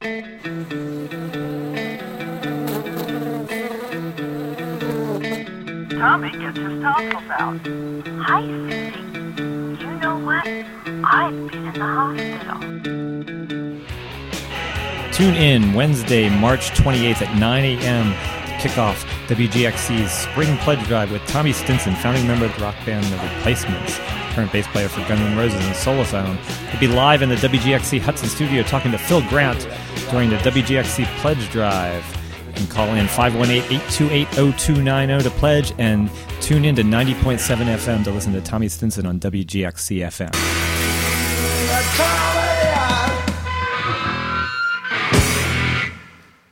WGXC Bee Your Media Pledge Drive PSA for Tommy Stinson Appearance (Audio)